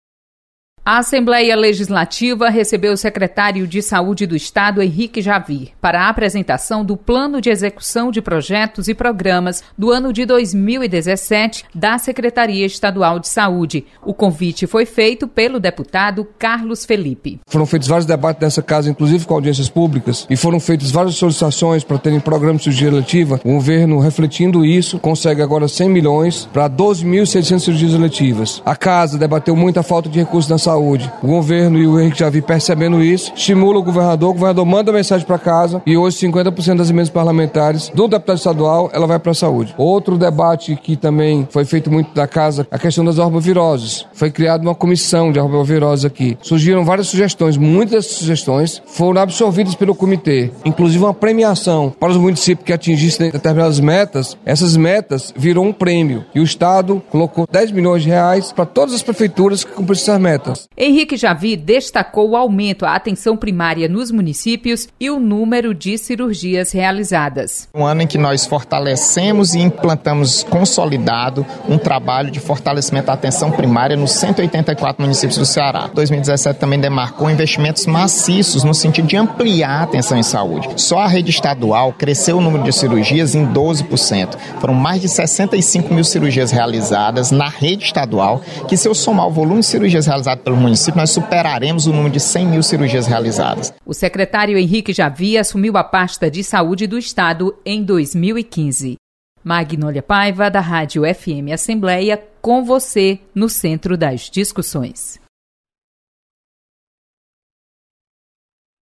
Secretário estadual de saúde apresenta trabalhos da Pasta. Repórter